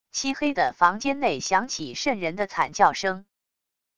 漆黑的房间内响起渗人的惨叫声wav音频